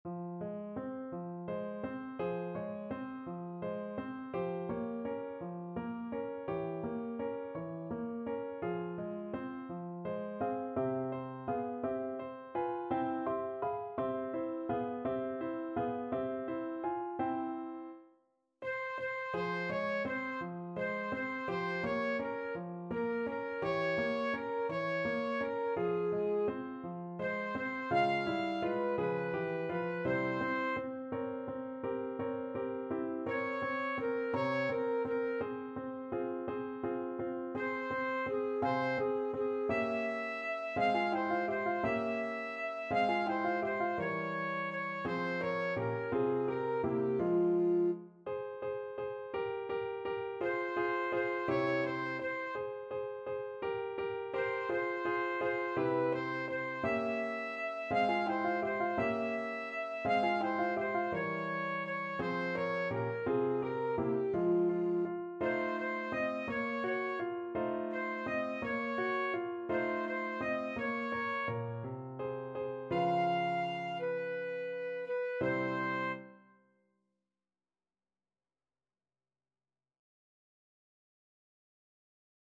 Alto Saxophone
F minor (Sounding Pitch) D minor (Alto Saxophone in Eb) (View more F minor Music for Saxophone )
6/8 (View more 6/8 Music)
. = 56 Andante
Classical (View more Classical Saxophone Music)